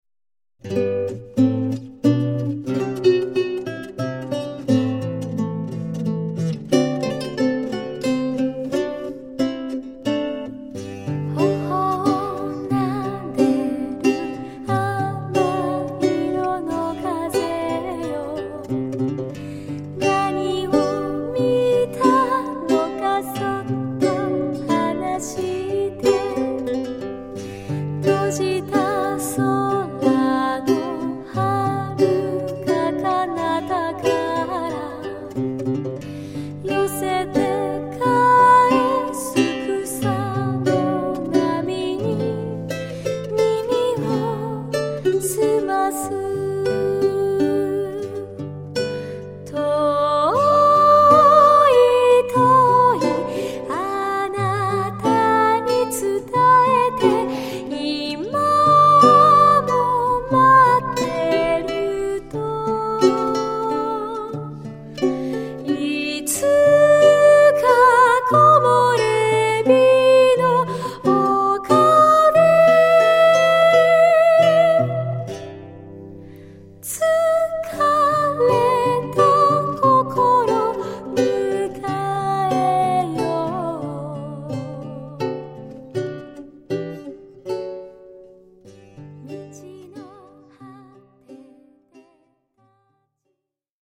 ヒュムノスとは全 く違う、だけどヒュムノスと同じ、心から癒されるような曲が出来たのではない かと思っています。